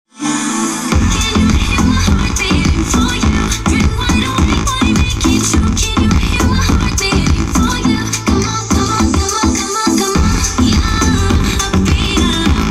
На одной колонке при добавлении громкости начинает бас рипеть это что конденсаторы по питанию менять?
Вот так играет один из каналов в чём может быть причина и только когда начинаешь накручивать больше 60 процентов,нужен совет.